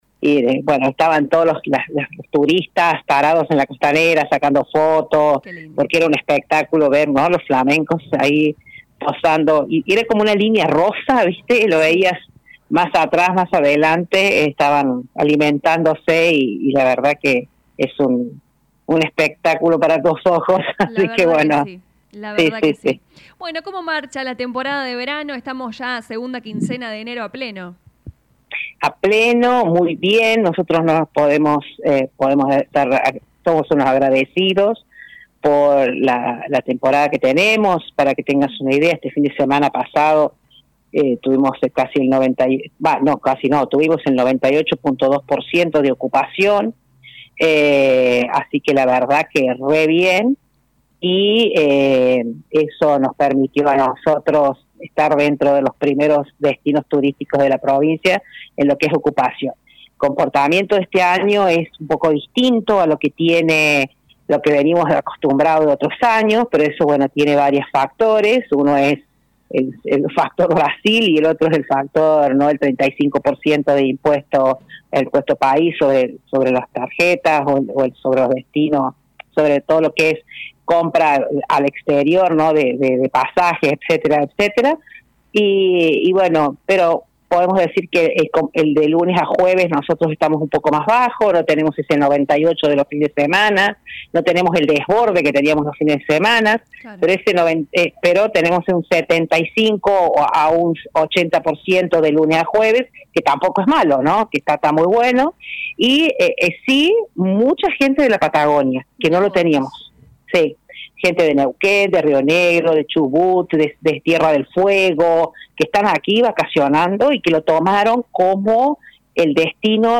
En diálogo con LA RADIO 102.9 FM la directora de Turismo Silvina Arrieta informó que hay furor de turistas que llegan desde la Patagonia Argentina buscando «sol, playa y calor».